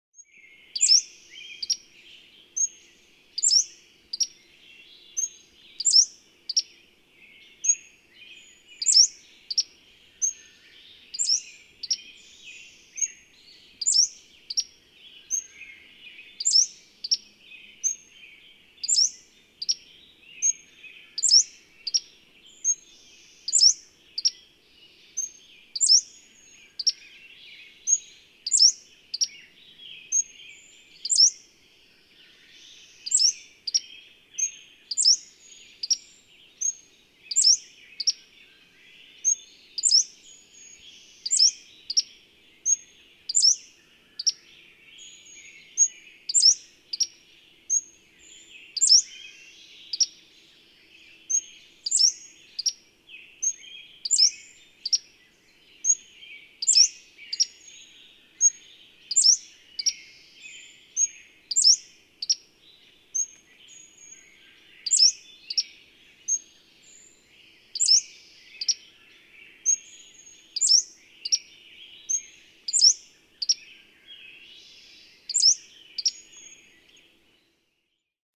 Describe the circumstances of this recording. William L. Finley National Wildlife Refuge, Corvallis, Oregon.